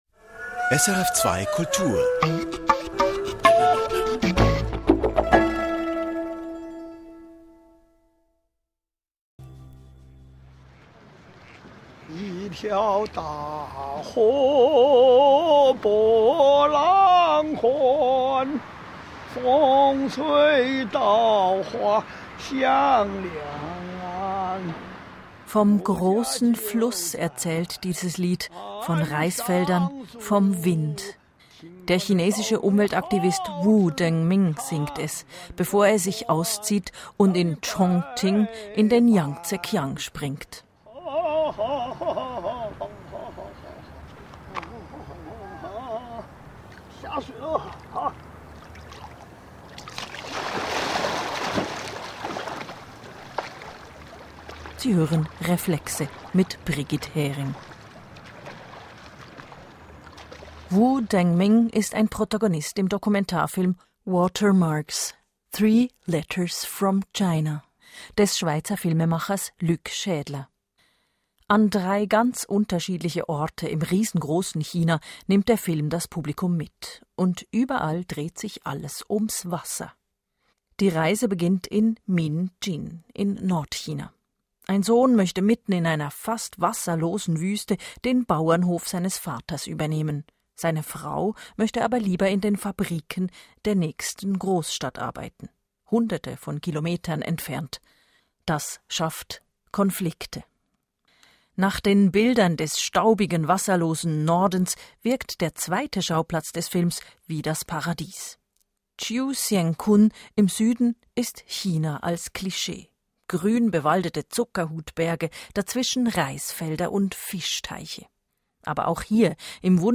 RADIOGESPRÄCH SRF 2